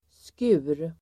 Uttal: [sku:r]